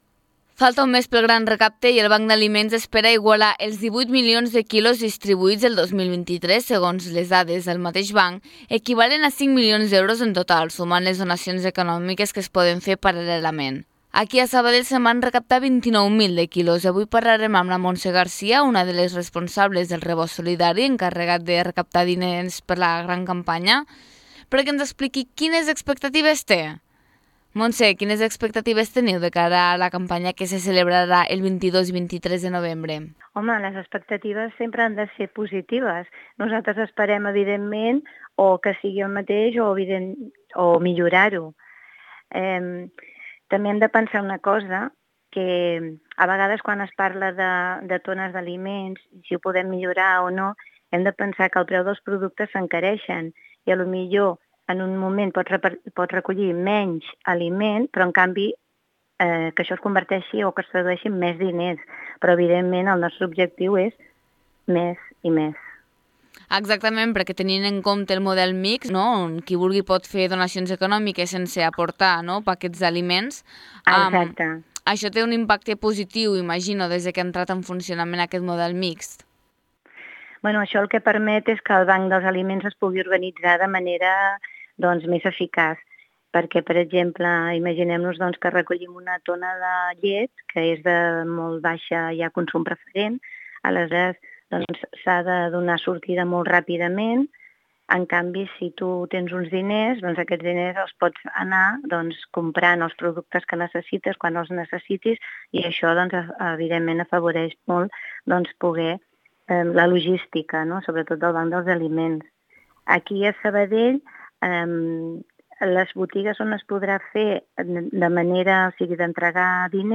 ENTREVISTA GRAN RECAPTE.mp3